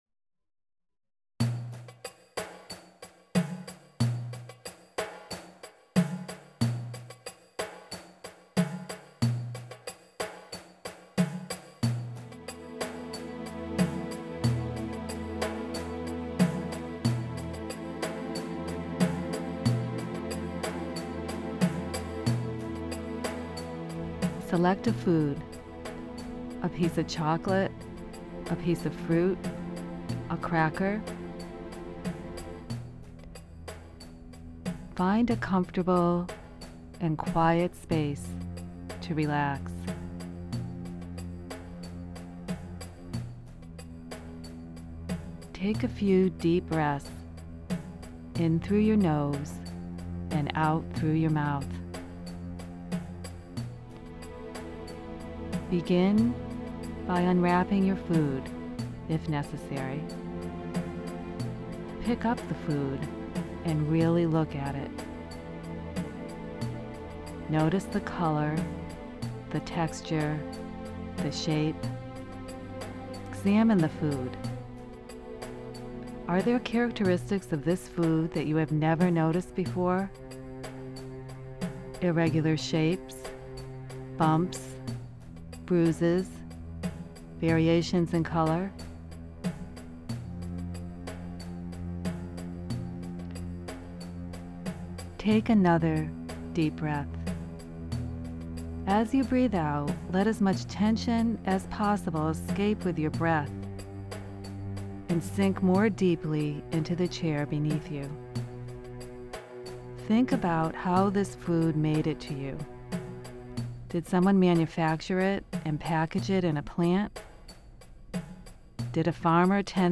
Eating Meditation